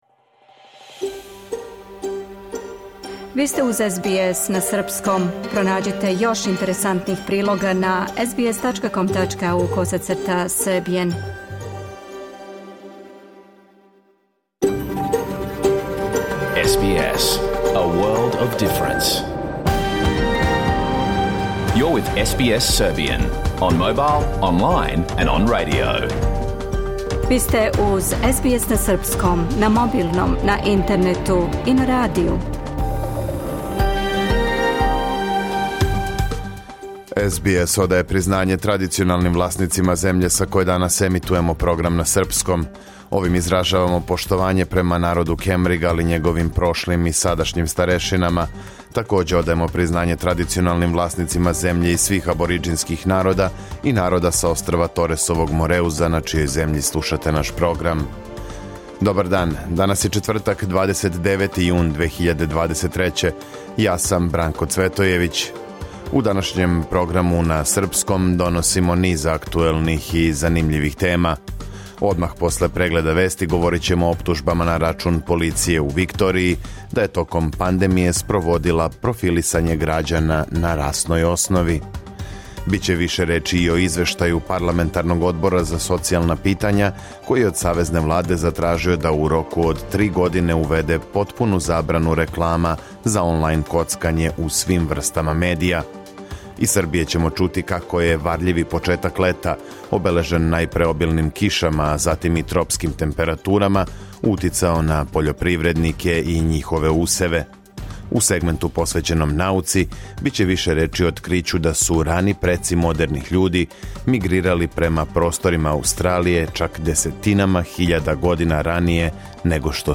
Програм емитован уживо 29. јуна 2023. године
Уколико сте пропустили данашњу емисију, можете је послушати у целини као подкаст, без реклама.